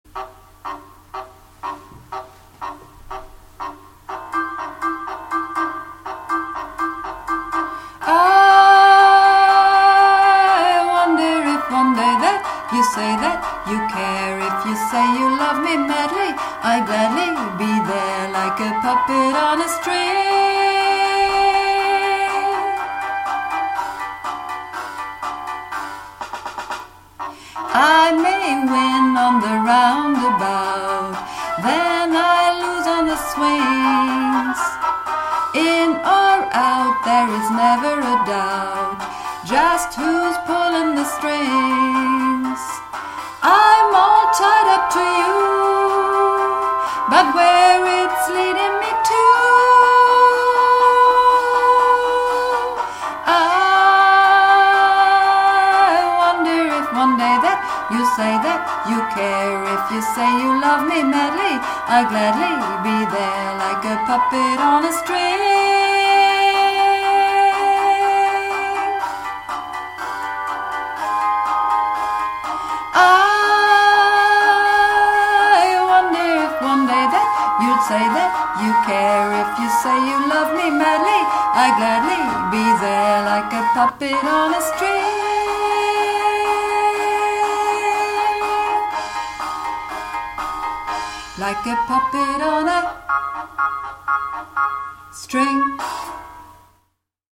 (Übungsaufnahmen)
Puppet On A String - Alt